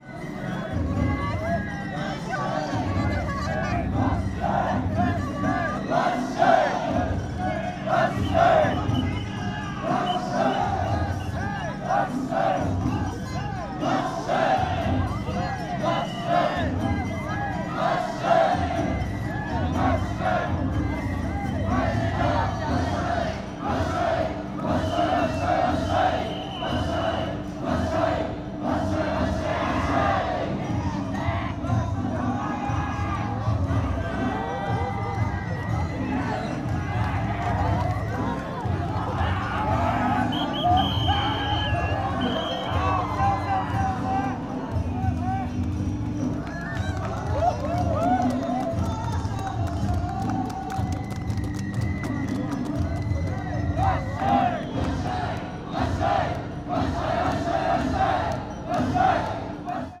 Fukushima Soundscape: Machi-naka Park